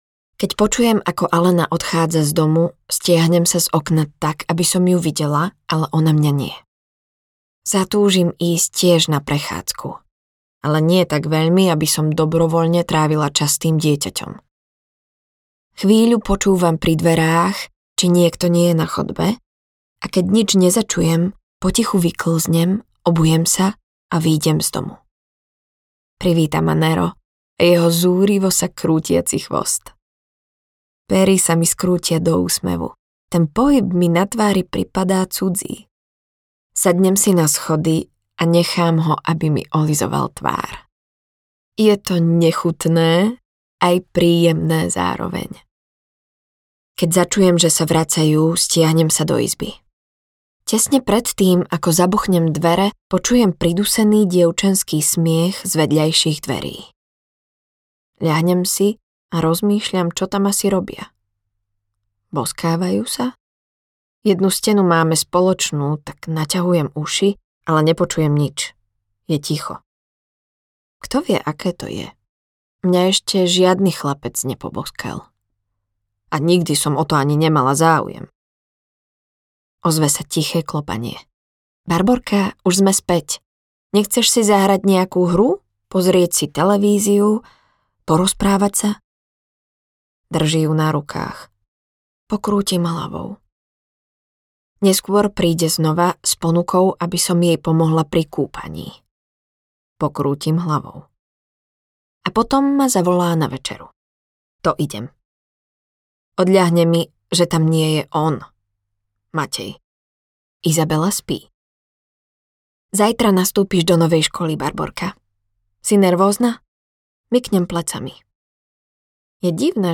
Otvorené nebo audiokniha
Ukázka z knihy